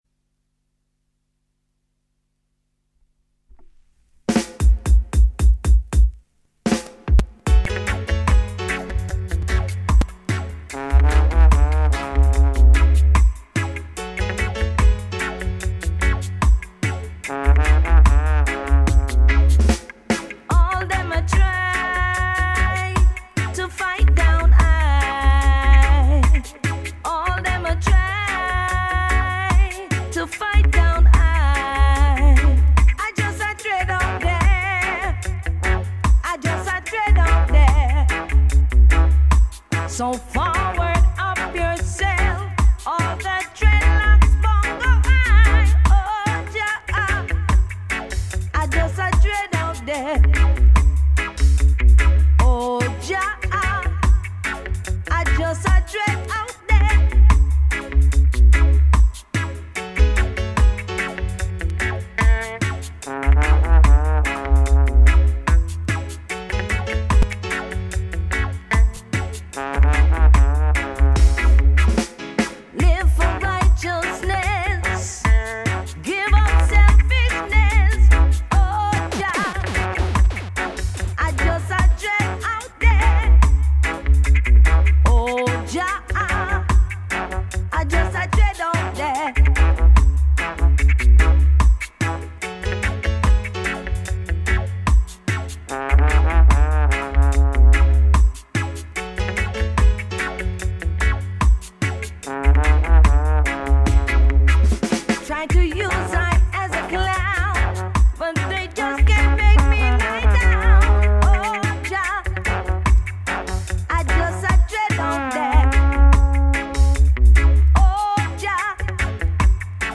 New chapter, Roots Rock Reggae.